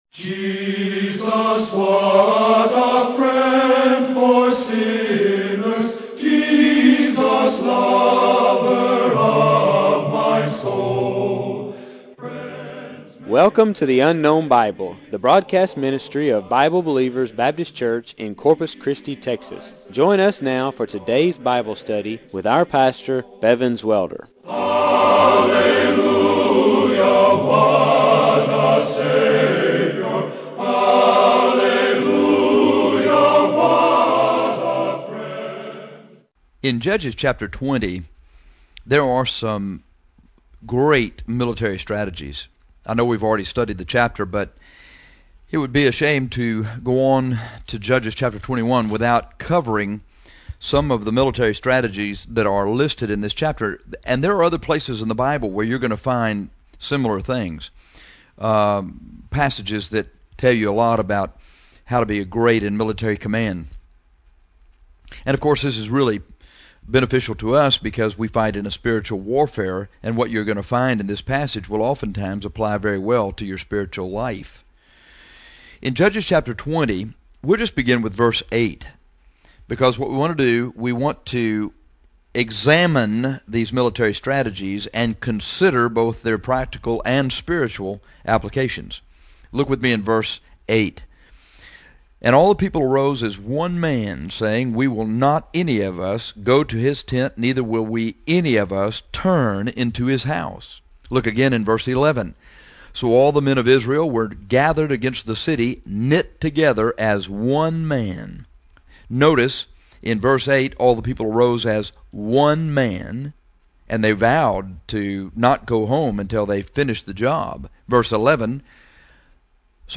Judges 20:8-35 Military Strategies Click title to listen to the radio broadcast